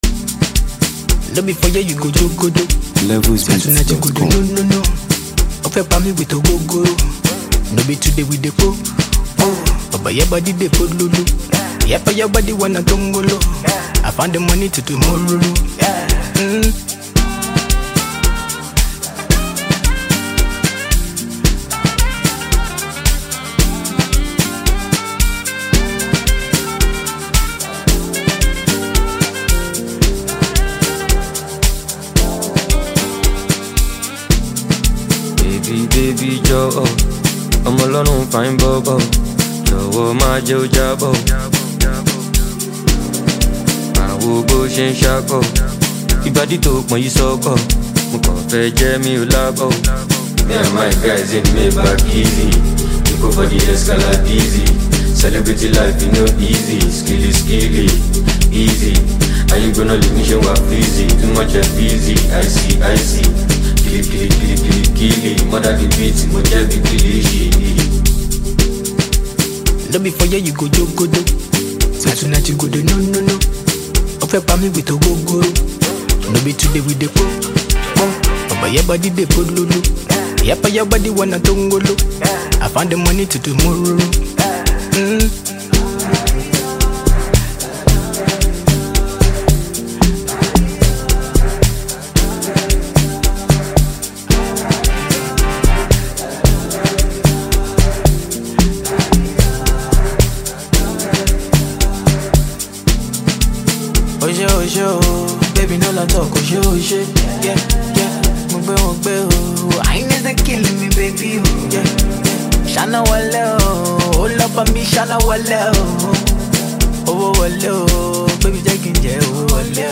street-pop
With its vibrant rhythm, catchy hooks
Afrobeats music